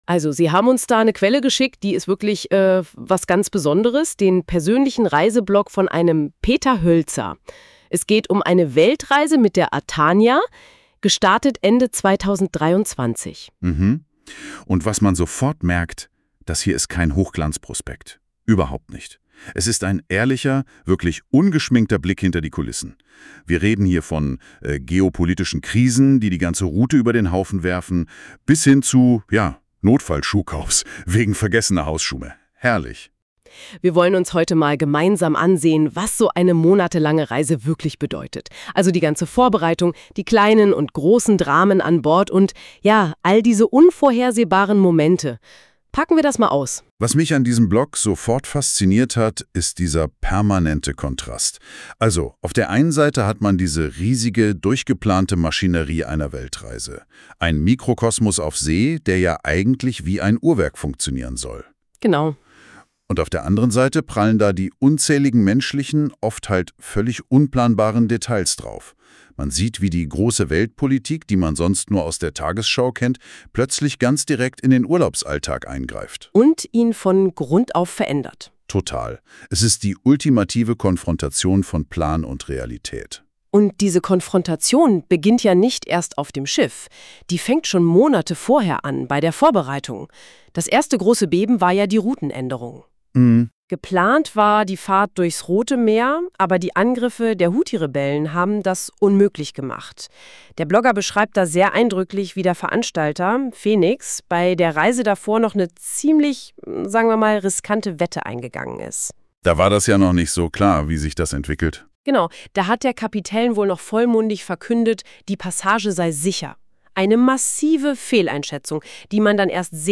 Neugierig wie ich bin, habe ich diese Schaltfläche angeklickt und nach ca. 10 Minuten hatte NotebookLM einen 17-miütigen Podcast erzeugt, der durchaus hörenswert ist. Gibt er nicht einfach Inhalte wieder, sondern in einem Zwiegespräch werden einige Teile und Passagen des Blogs erörtert und zwar unter den verschiedensten Blickwinkeln.